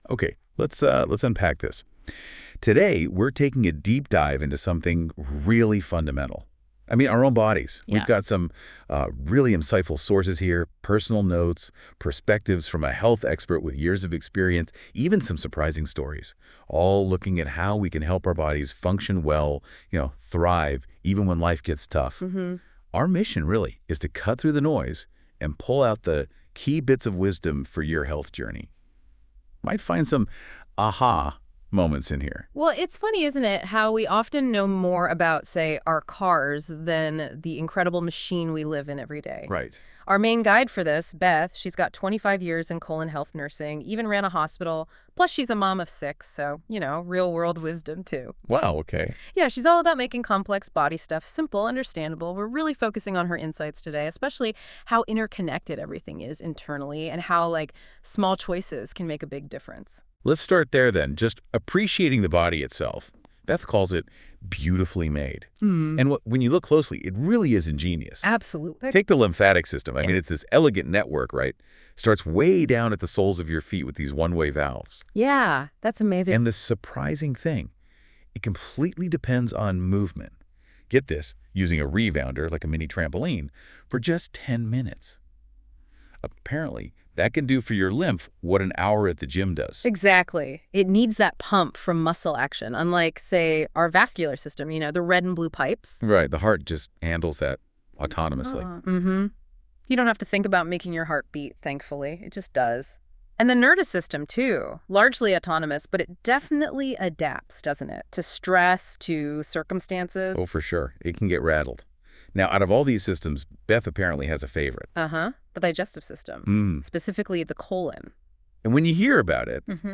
This podcast prepared using AI thoroughly explains the digestive system and its connection to overall health, advocating for natural, whole foods and regular eating intervals to support colon function.